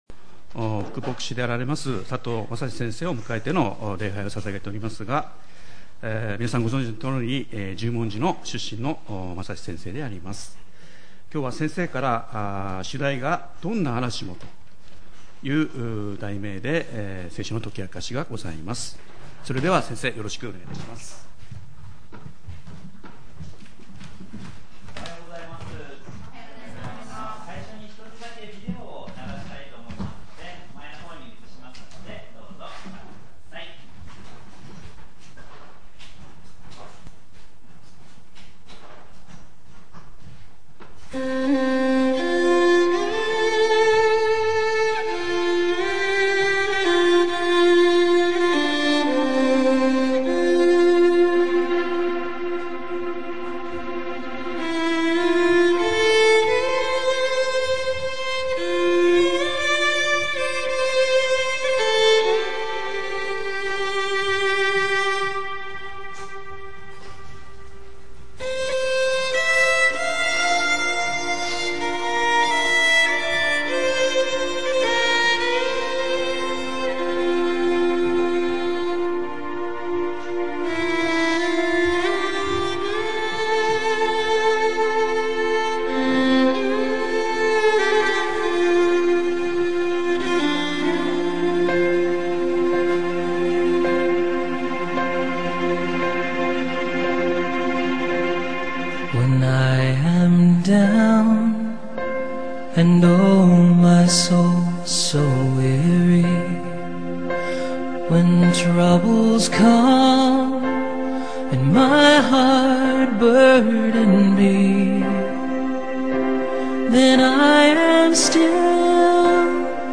●主日礼拝メッセージ（MP３ファイル、赤文字をクリックするとメッセージが聞けます）